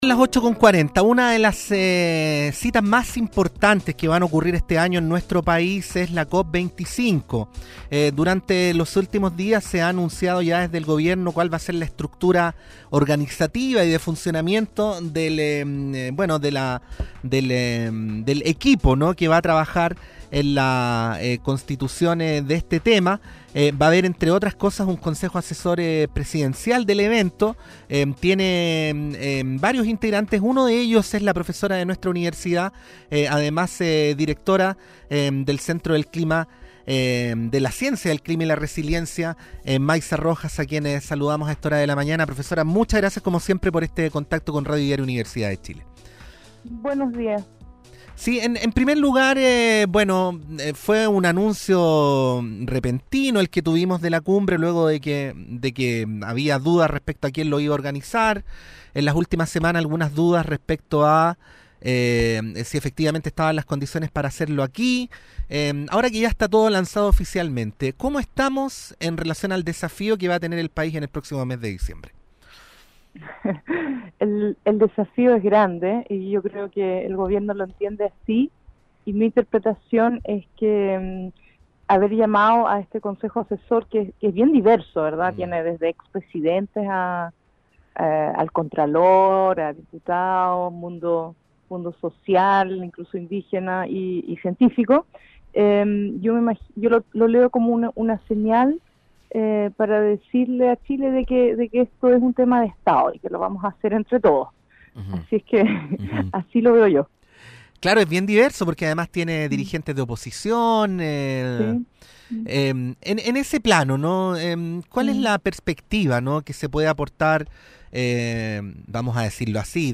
Escucha la entrevista en Radio U. de Chile.